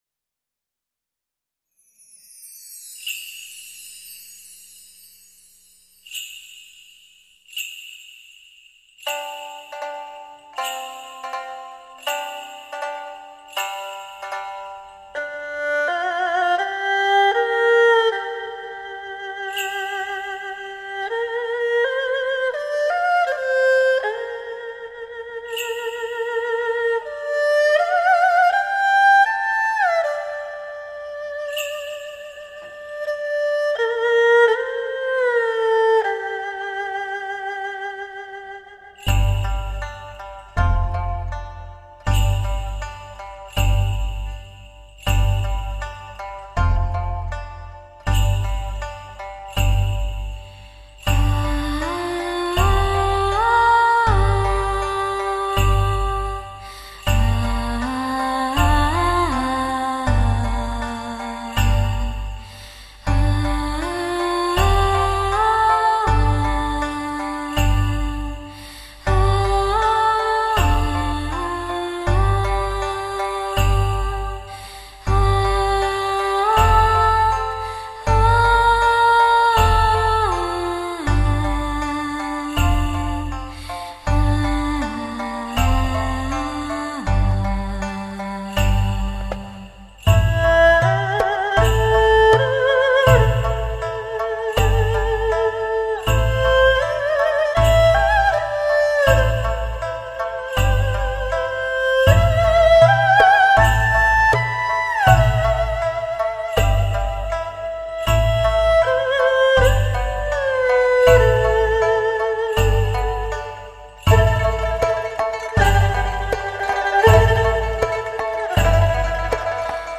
[4/11/2009]十面埋伏 佳人独舞：《佳人曲》（二胡&人声吟唱） 激动社区，陪你一起慢慢变老！